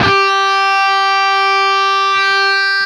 LEAD G 3 CUT.wav